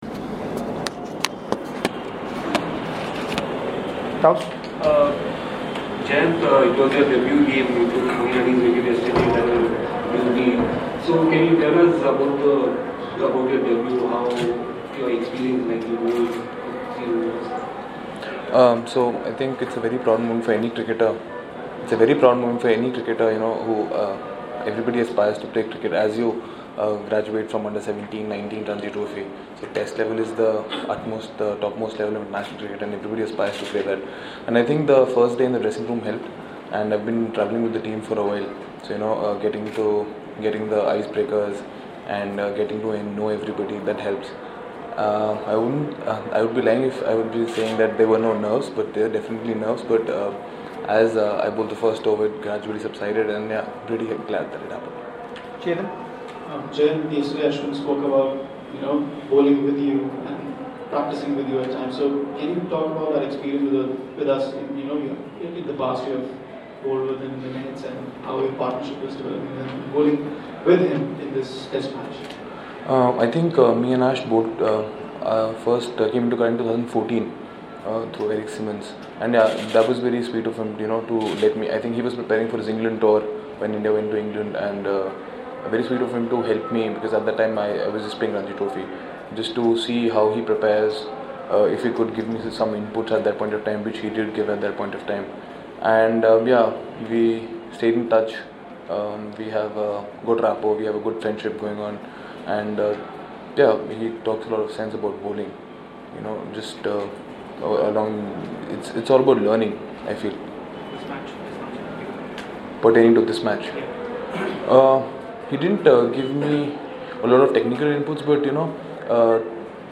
Jayant Yadav during the press conference on Day 3